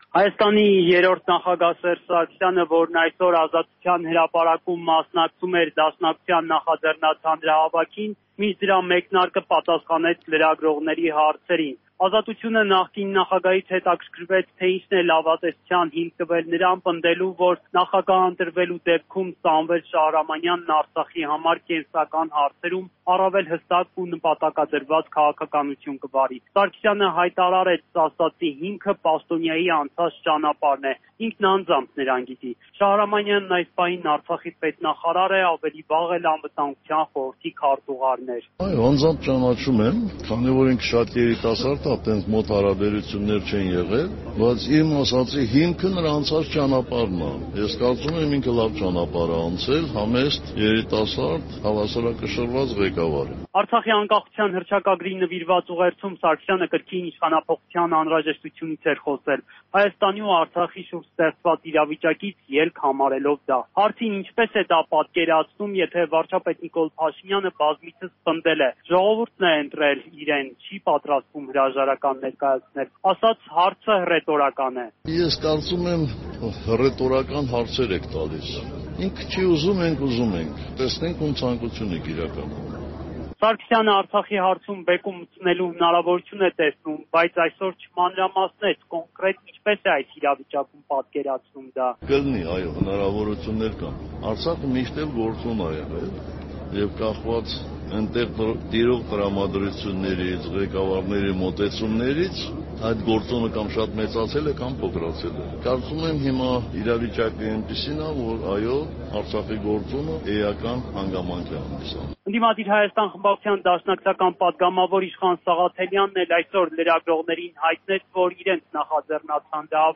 Հանրահավաք Ազատության հրապարակում՝ իշխանափոխության պահանջով